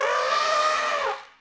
these are two of those elephant trumpets that I'm using.